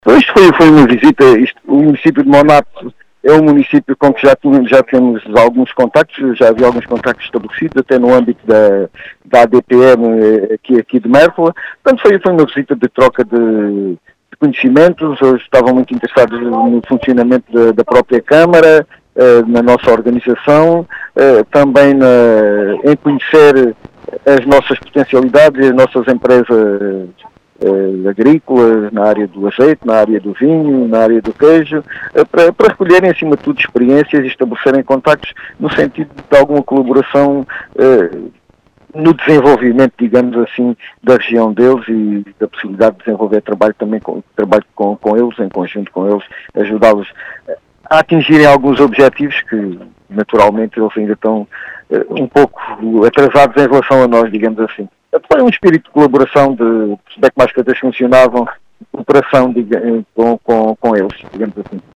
“Uma troca de conhecimentos”, segundo explicou à Rádio Vidigueira, o presidente da Câmara Municipal de Serpa, João Efigénio Palma.